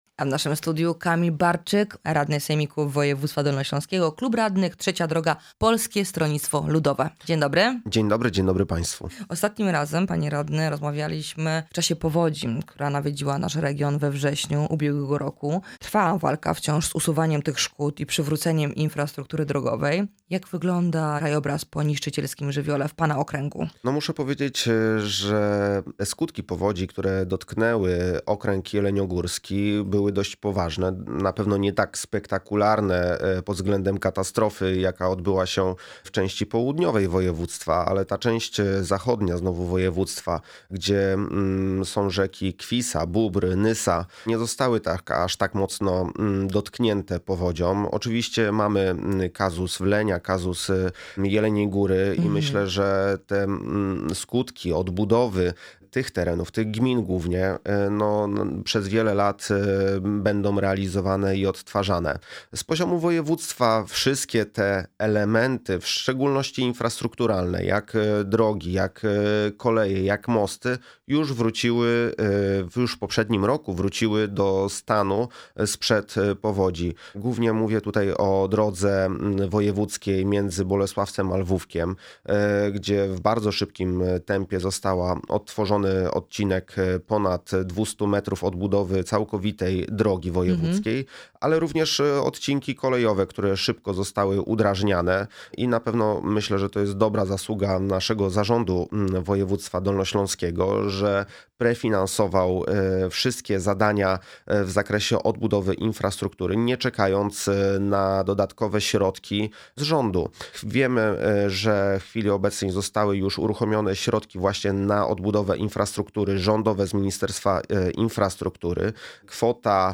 Radny Sejmiku Kamil Barczyk w audycji „Dolny Śląsk z bliska”
O usuwaniu skutków powodzi i przywracaniu infrastruktury po żywiole, który nawiedził nasz region we wrześniu ubiegłego roku, zaawansowaniu prac budowlanych szpitala onkologicznego oraz nowych połączeniach kolejowych rozmawiamy z naszym gościem. Jest nim Kamil Barczyk radny Sejmiku Województwa Dolnośląskiego (Klub Radnych: Trzecia Droga – Polskie Stronnictwo Ludowe).